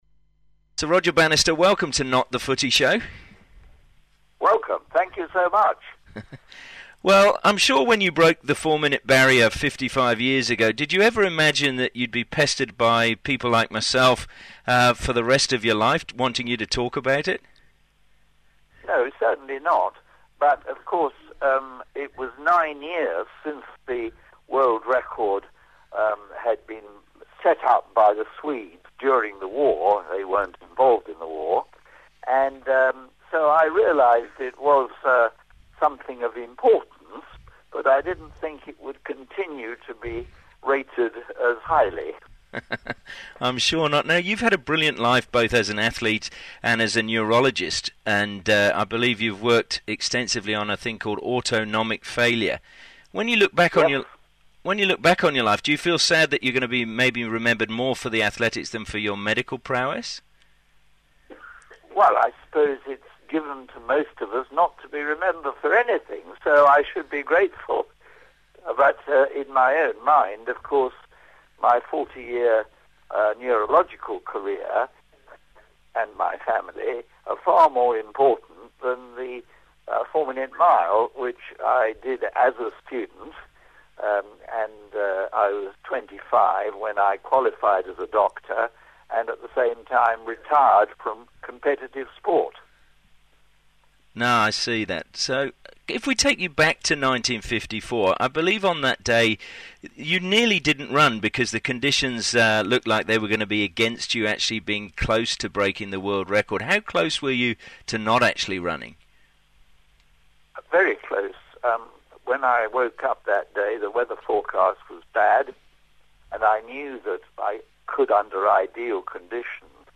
Several people have since contacted to ask if we could re-play the interview.